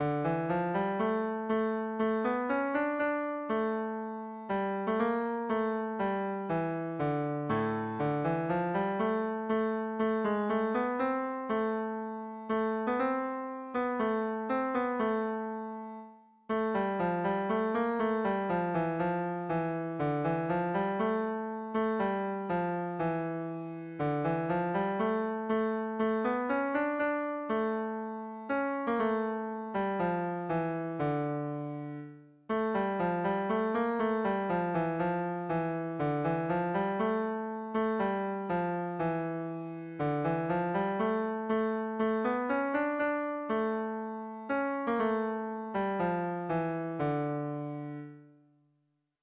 Erlijiozkoa
A1A2BA3